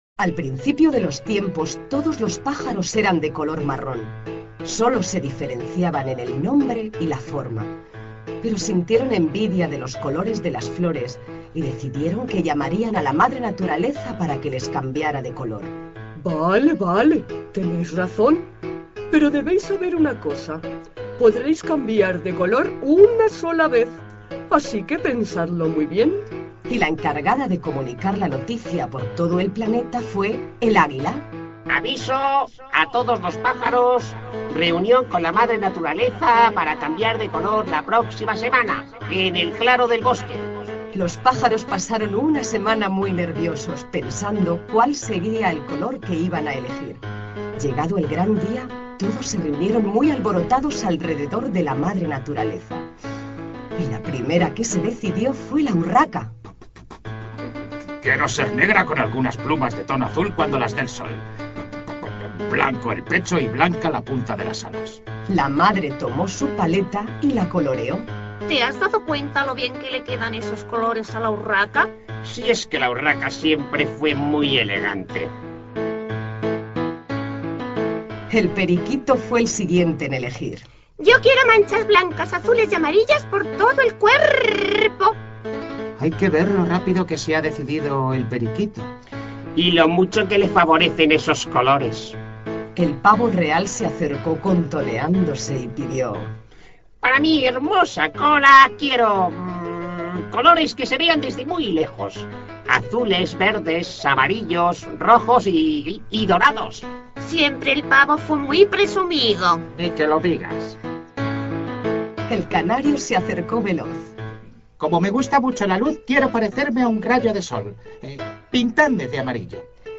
cuento
Cuentos infantiles